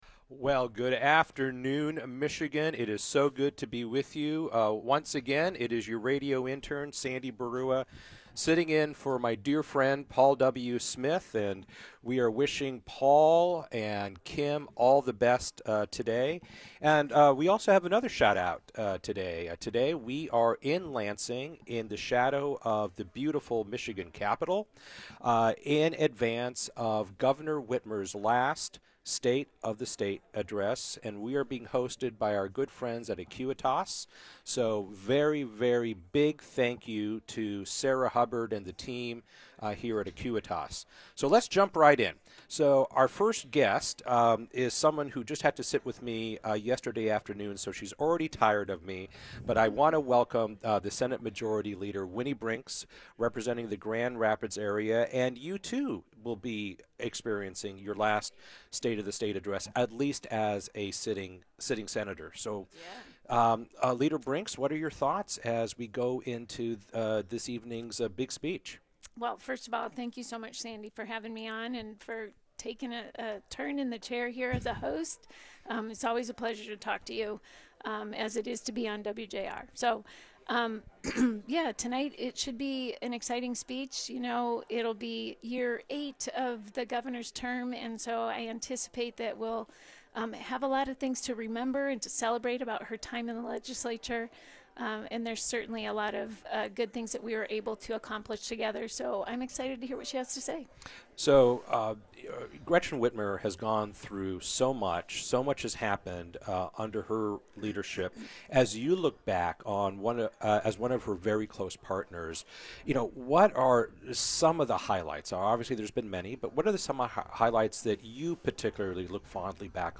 interviewing several prominent leaders in the Detroit Region as they awaited Governor Gretchen Whitmer’s final State of the State address.